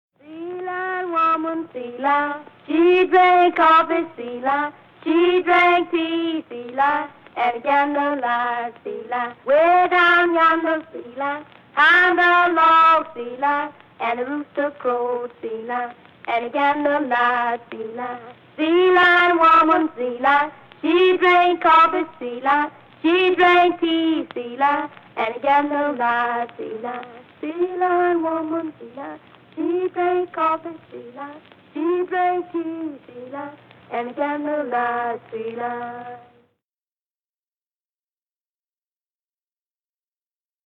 Field recordings paired with these images were recorded in rural Mississippi by John and Alan Lomax between 1934 and 1942.